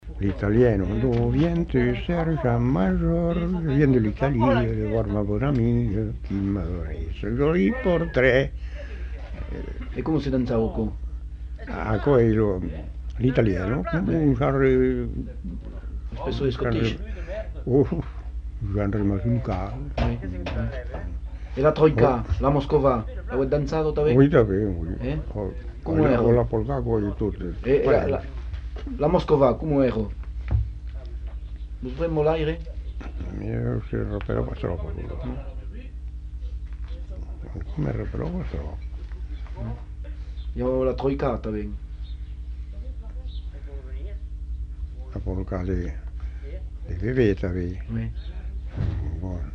Lieu : Simorre
Genre : chant
Effectif : 1
Type de voix : voix d'homme
Production du son : chanté
Danse : italienne